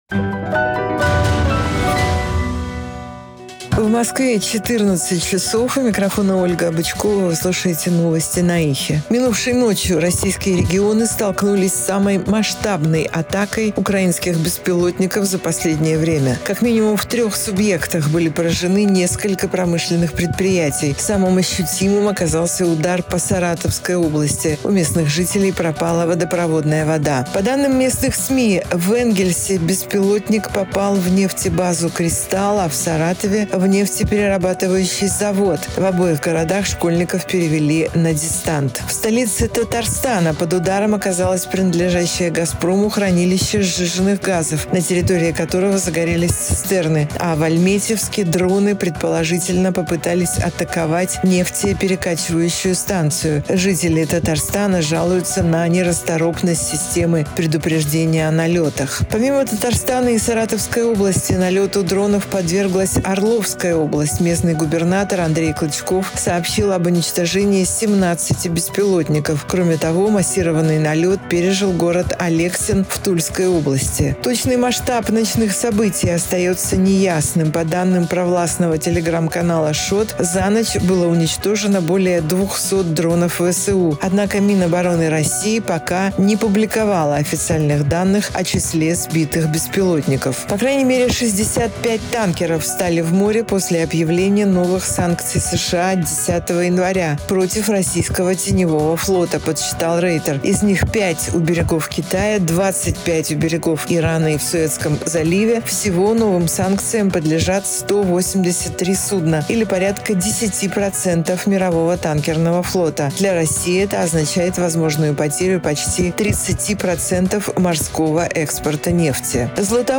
Слушайте свежий выпуск новостей «Эха»
Новости 14:00